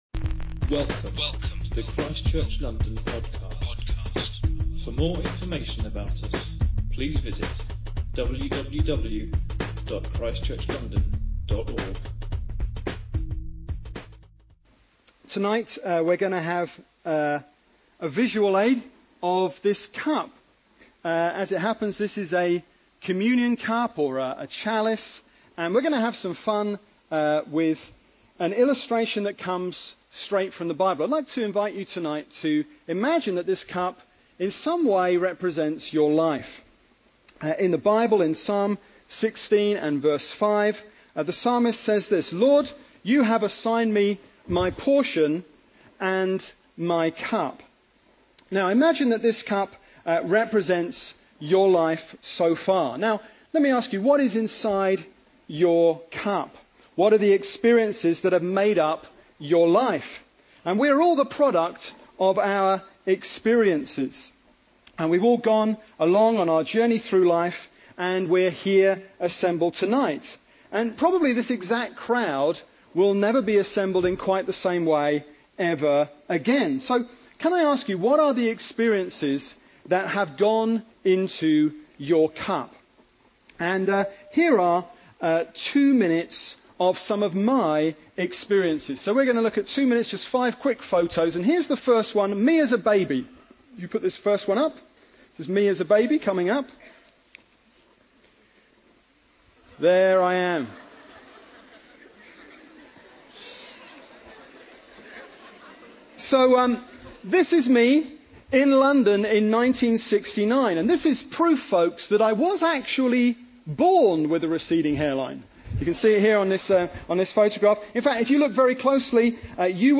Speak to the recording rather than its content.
Preaching from ChristChurch London’s Sunday Service